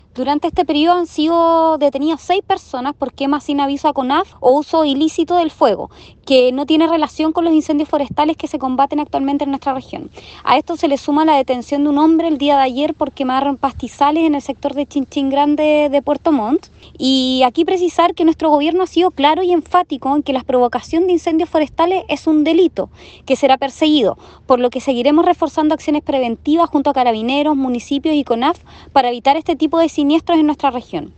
La información fue entregada por la Delegada Presidencial de la Región de Los Lagos, Giovanna Moreira. La autoridad aclaró que en este período se han detenidos a seis personas por quemas no autorizadas y que no tienen relación con los incendios forestales que se combaten actualmente, al que se suma un detenido durante la jornada de ayer en el sector de Chin Chin en Puerto Montt.